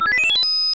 Category:Super Mario Maker sound effect media files
SMM_SMW_Door_Key_Appear.oga